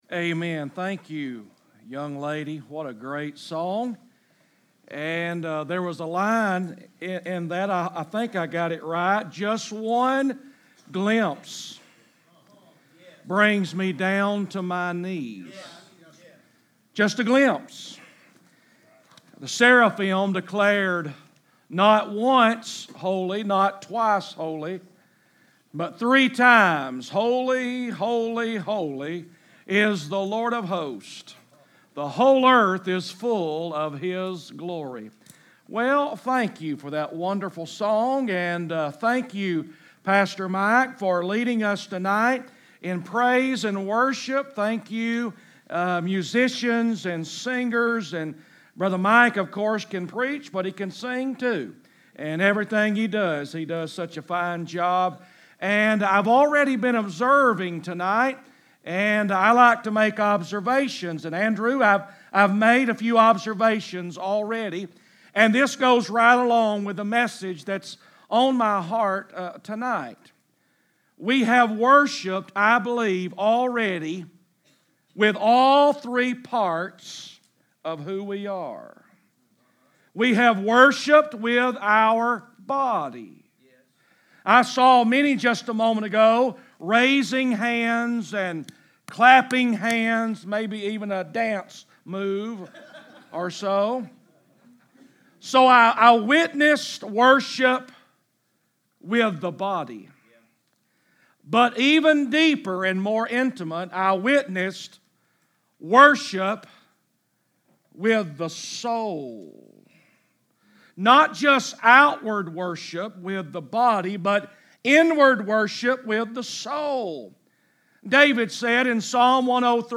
From the evening session of ESM Summer Camp on Tuesday, June 25, 2019